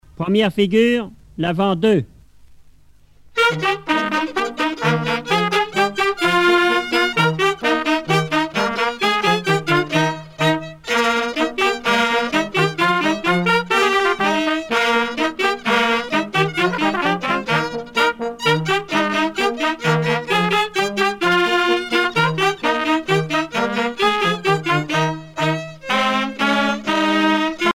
danse : quadrille : avant-deux
groupe folklorique
Pièce musicale éditée